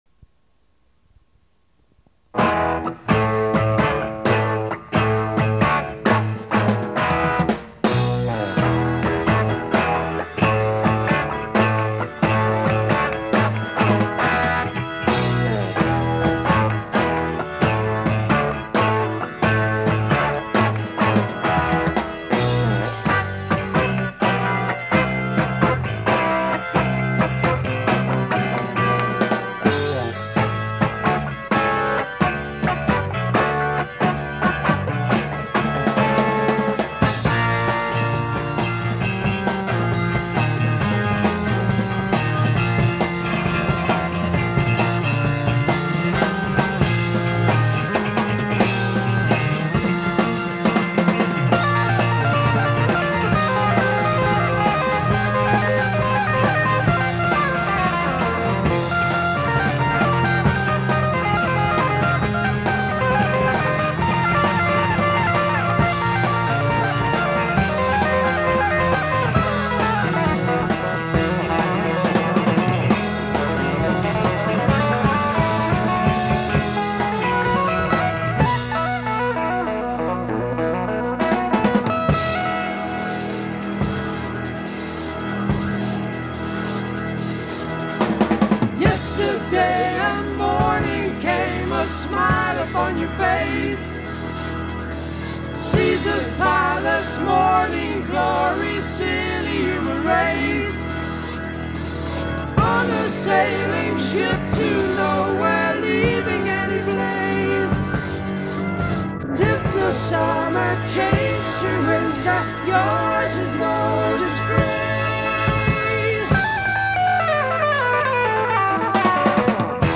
The Progressive Rock Era and America.